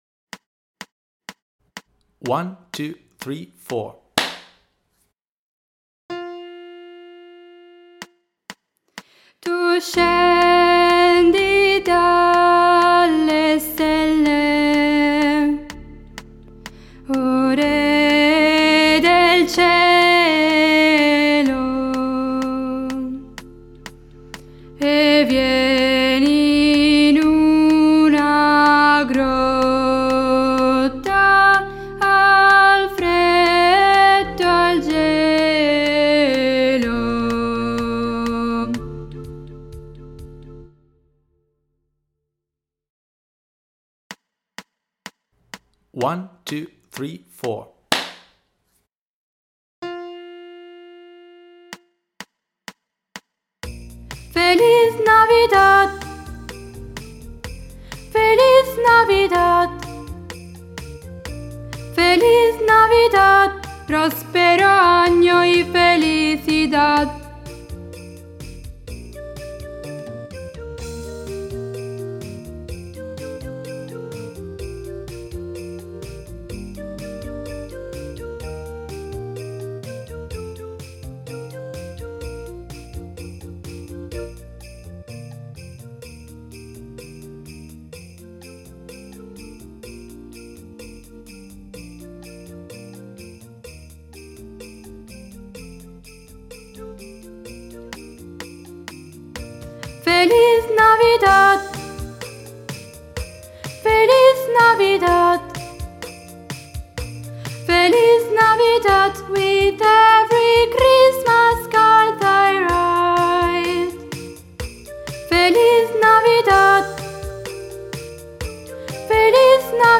Per partecipare alla nostra iniziativa dovresti studiare la canzone di Natale di Feniarco: un arrangiamento con 10 melodie natalizie, che si alternano tra i diversi registri vocali, realizzato appositamente per l'occasione da Alessandro Cadario.
• una linea melodica dedicata alle voci bianche
Mp3 traccia guida voci bianche